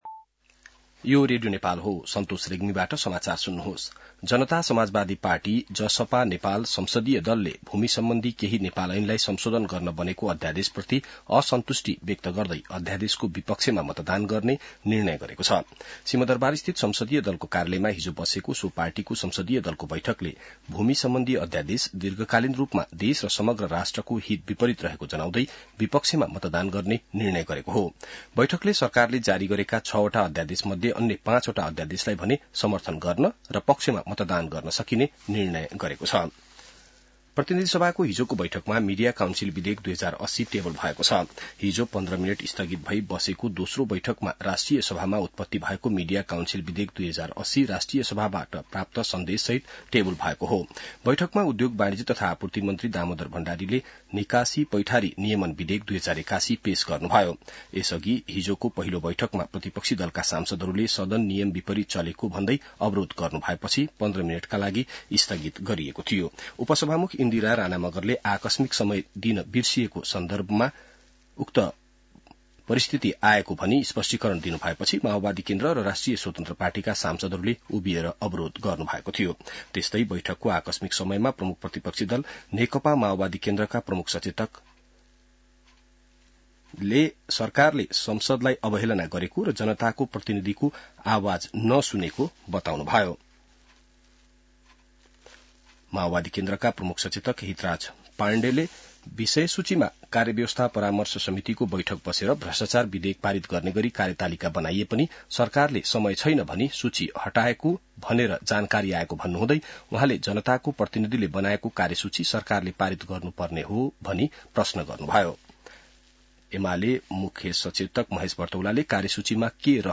बिहान ६ बजेको नेपाली समाचार : १ फागुन , २०८१